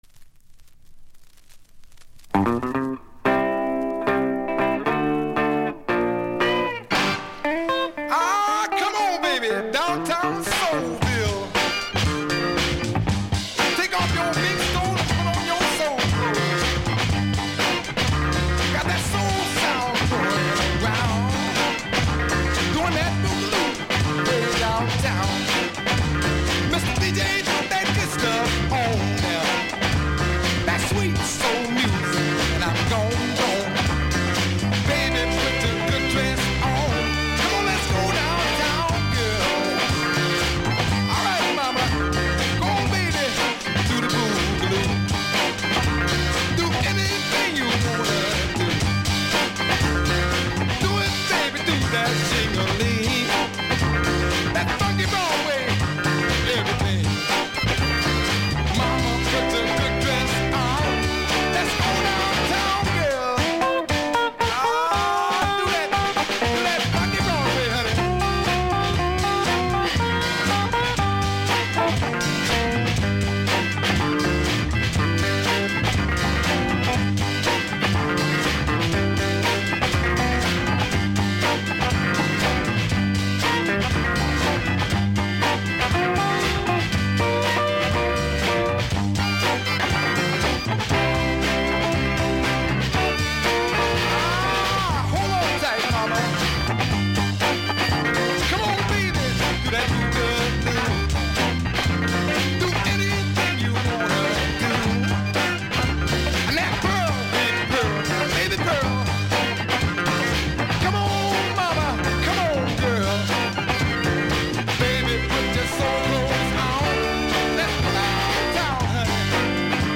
45 Music behind DJ